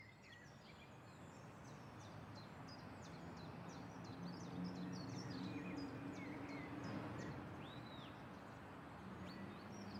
1-morning-with-birds.e3ab105037e40d750f38.mp3